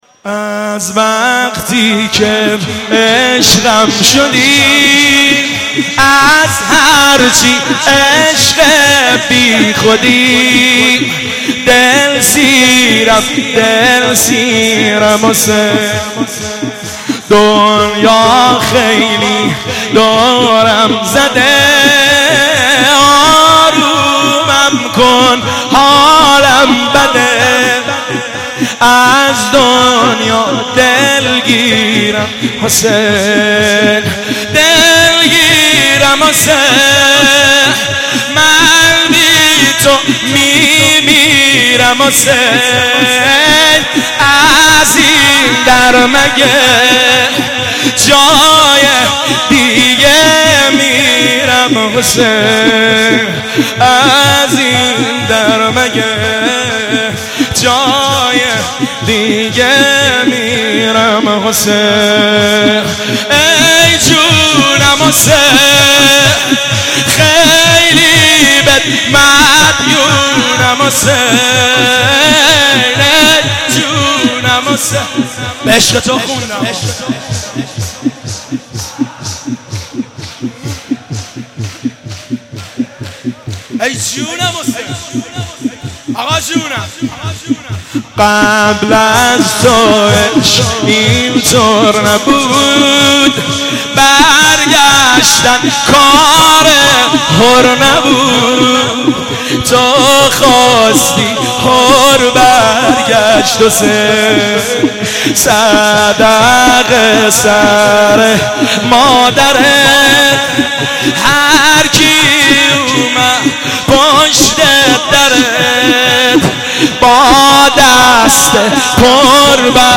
مناسبت : شب بیست و یکم رمضان - شب قدر دوم
قالب : شور